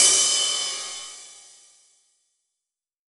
9CRASH 2.wav